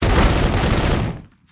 sfx_skill 07.wav